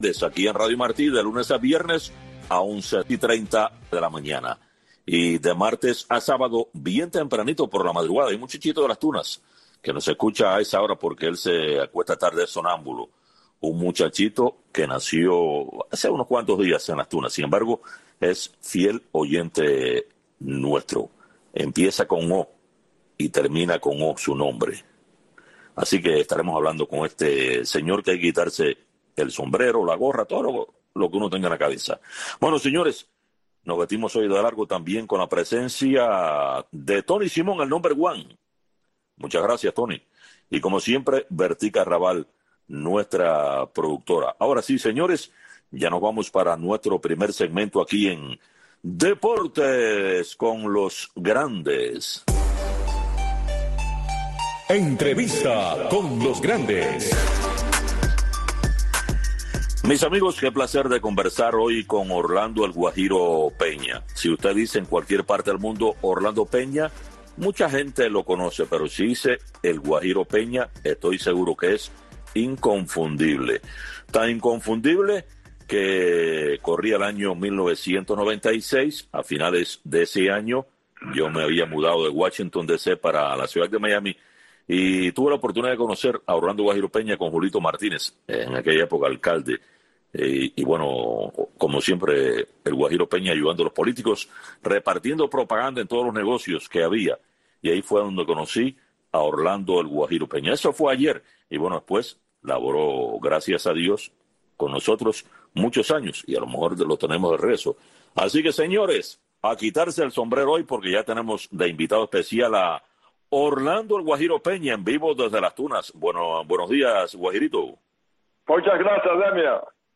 Entrevistas, comentarios y análisis de los grandes acontecimientos del deporte.